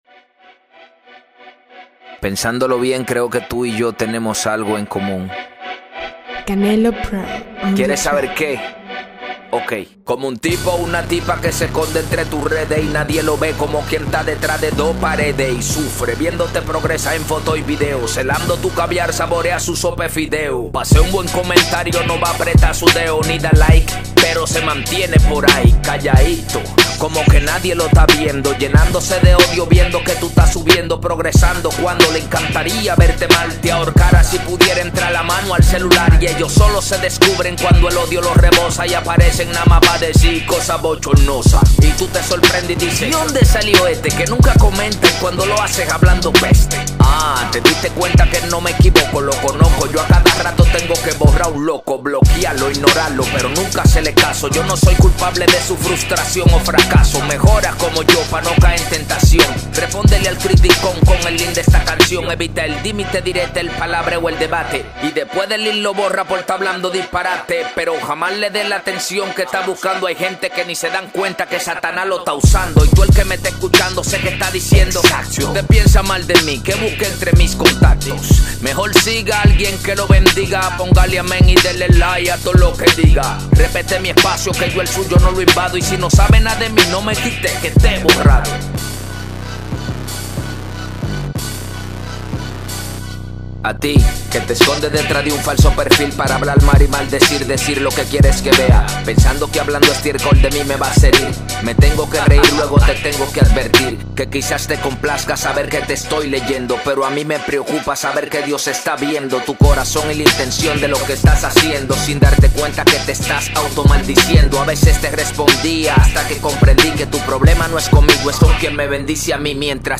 música rap
video musical cristiano